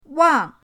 wang4.mp3